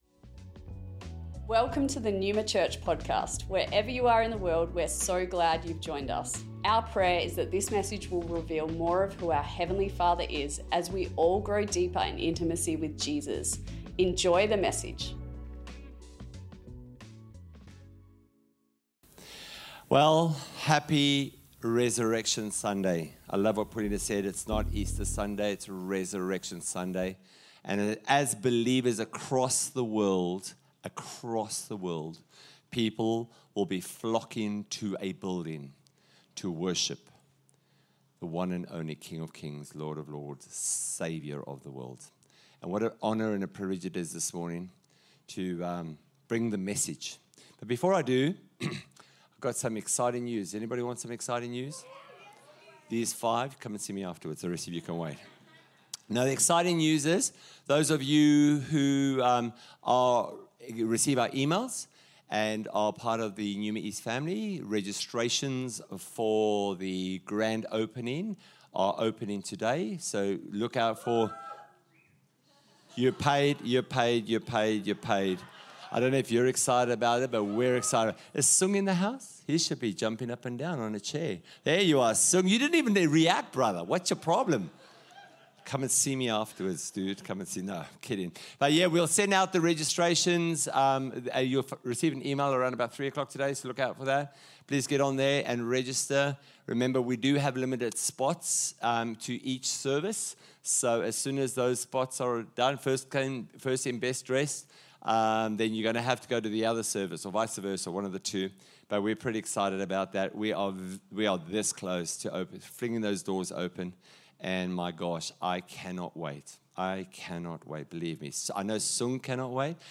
Neuma Church Melbourne East Originally recorded on Resurrection Sunday, 20th of April 2025 | 9AM